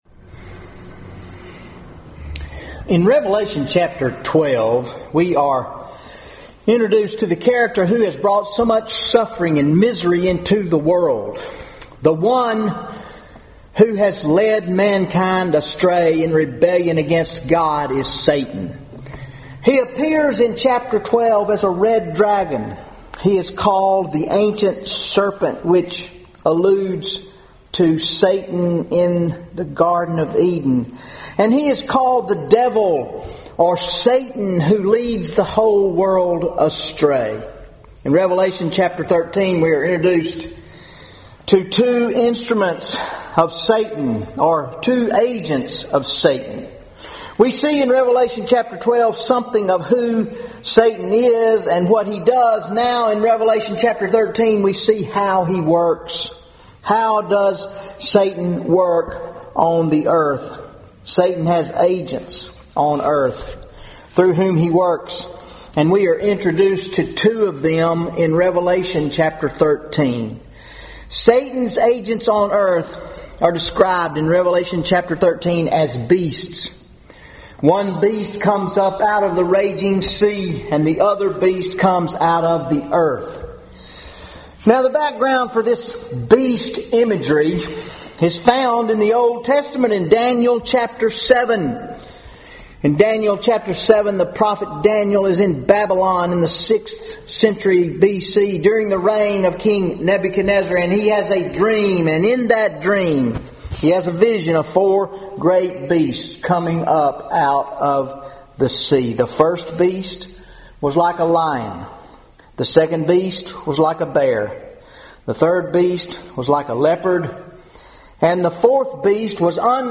Sunday Sermon 11/23/14 Revelation 13 How Does Satan Work on the Earth?